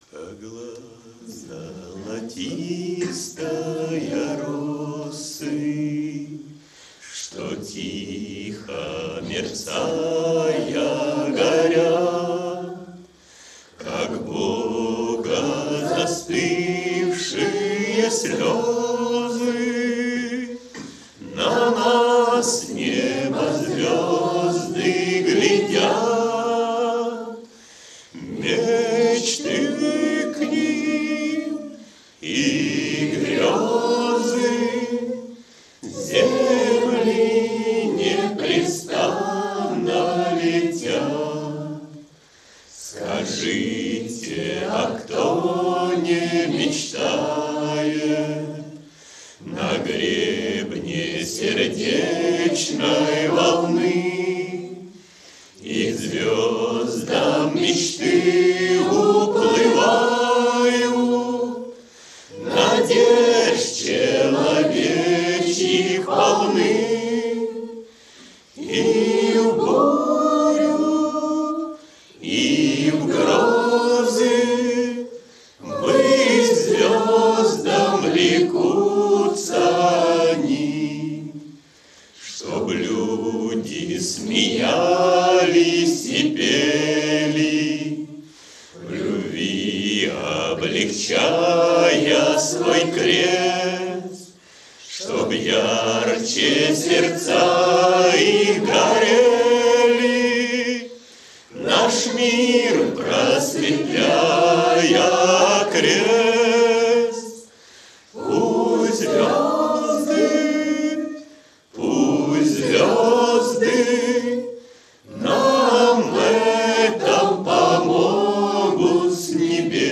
кавер-версия
акапелла